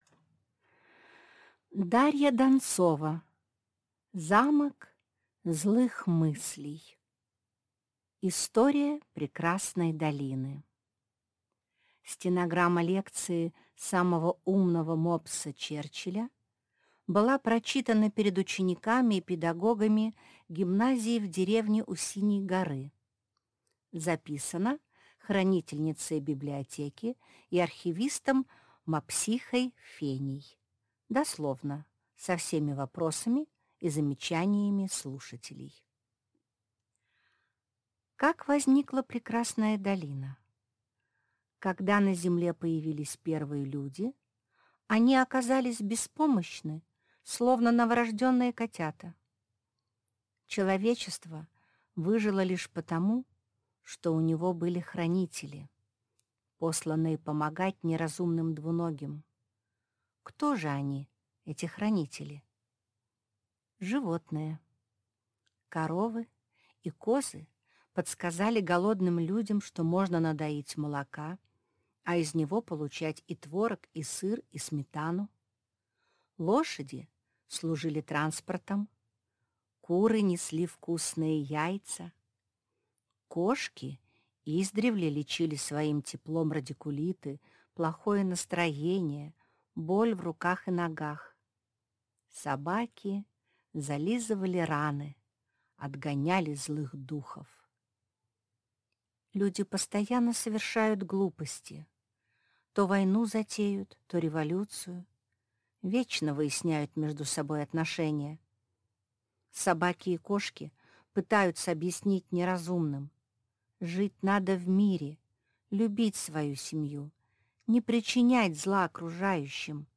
Аудиокнига Замок злых мыслей | Библиотека аудиокниг